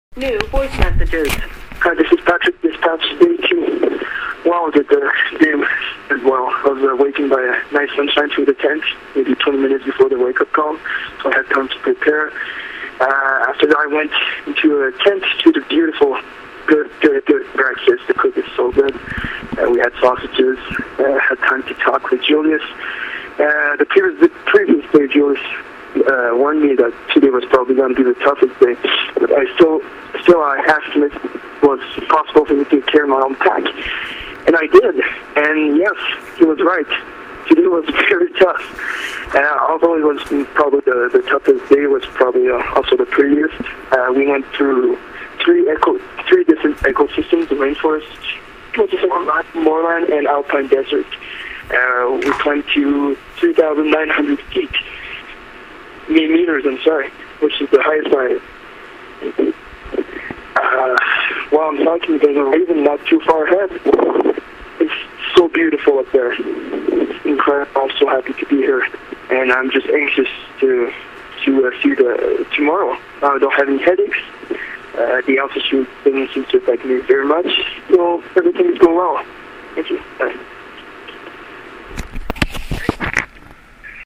Kilimanjaro Expedition Dispatch